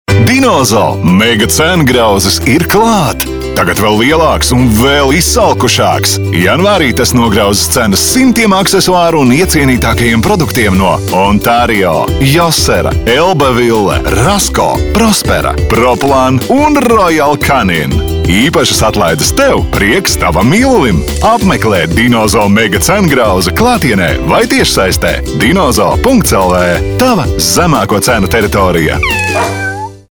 RADIO REKLĀMAS